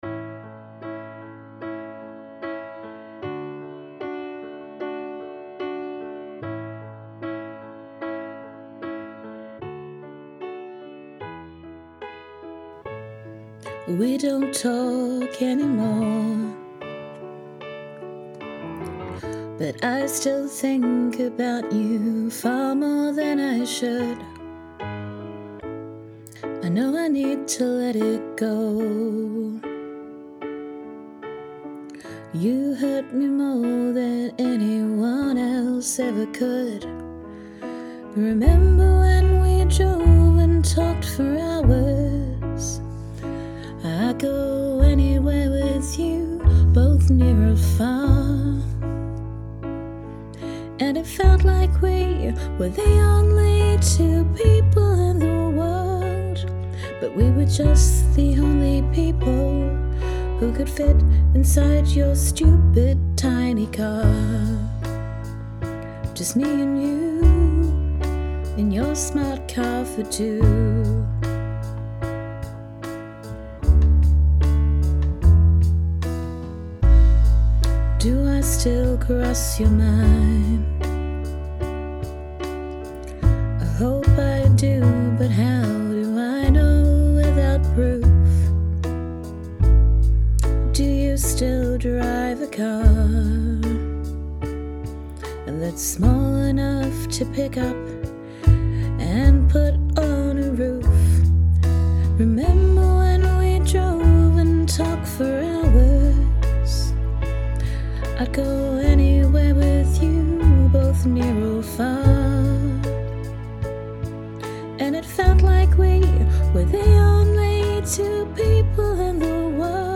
Arrangements made with Band In A Box. Vocals recorded late at night trying not to wake up flatmates.
You have a gorgeous tone, I feel like I could listen to you sing the news.
Also - for a late night tryna be quiet session, this is beautifully sung.
yup the freedom to tell you it your own way! ha ha that did turn around! very effective simple piano line and your vocals tell the story so well.
The first song I listened to on this FAWM album is a really beautiful ballad!
The musical arrangement is very well done, and you have a lovely voice! The lyrics are very clear and well-articulated!